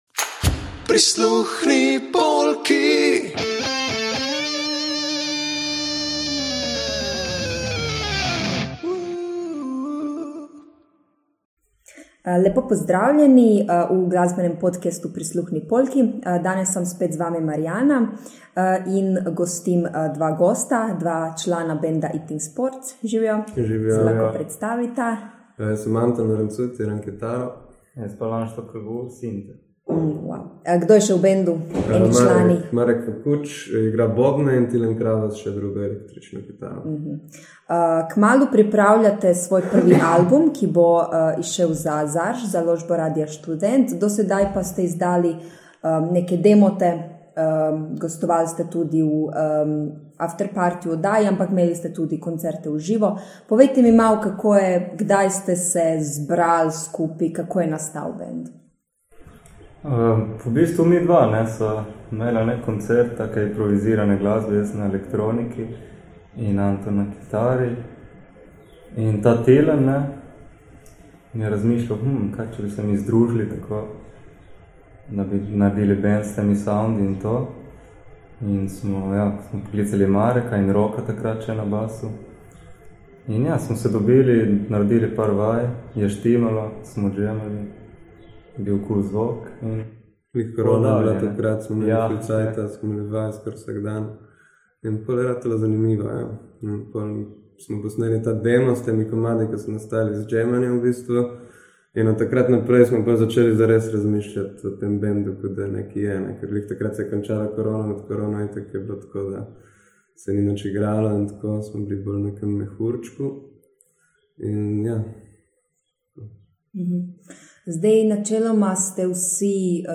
Eating Sports je nova domača zasedba, katere glasbeni slog se giblje med jazzom, rockom in elektronsko glasbo in v igrivem plesu tvori psihedelično fuzijo.